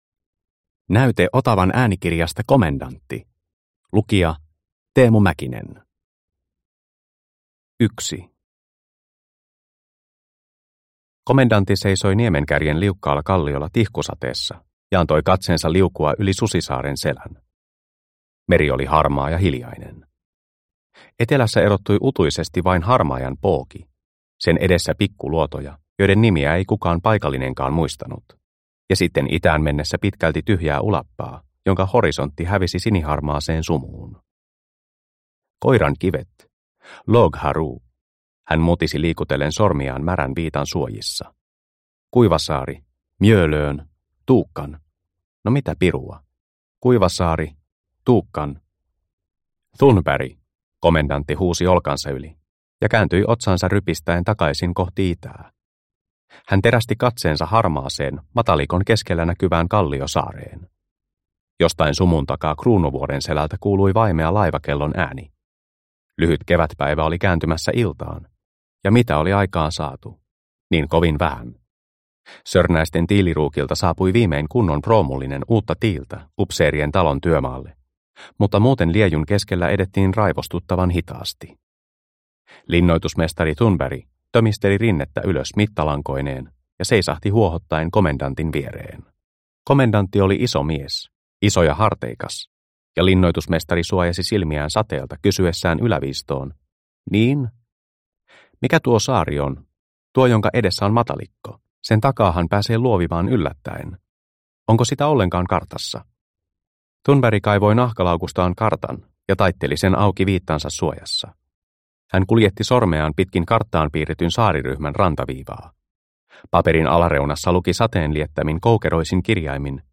Komendantti – Ljudbok – Laddas ner